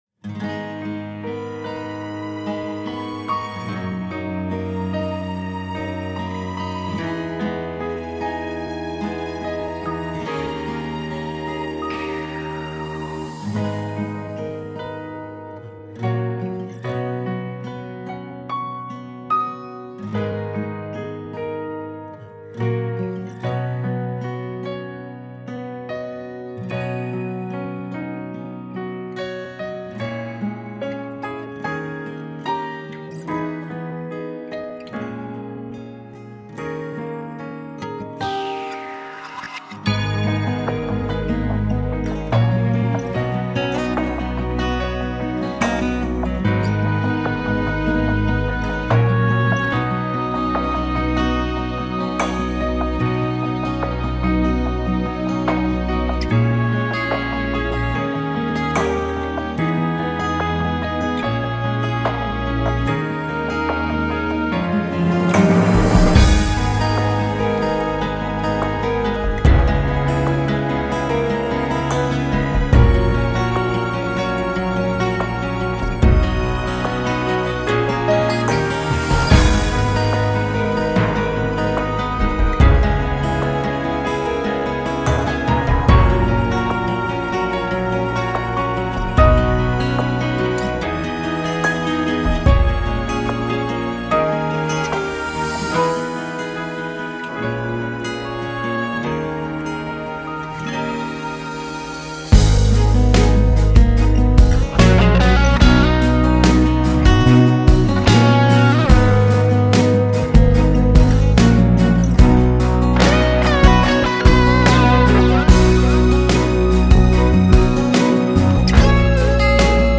Песня со словами (плюс)